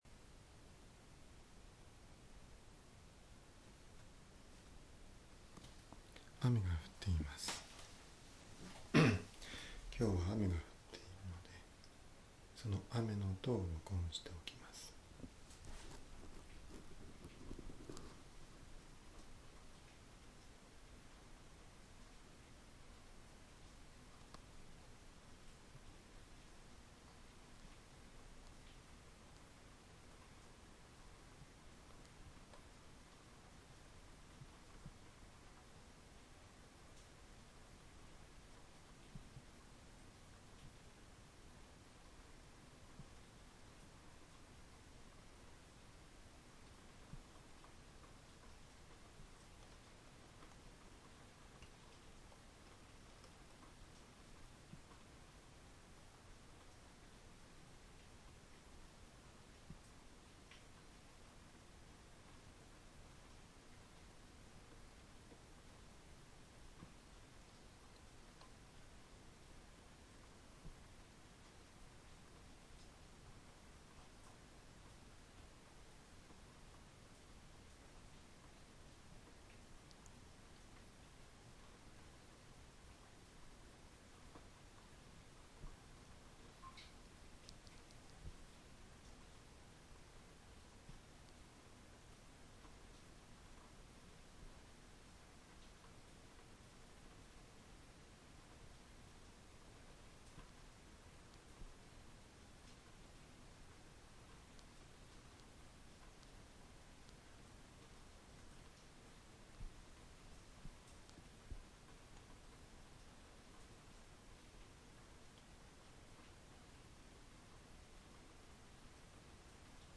rain
38724-rain.mp3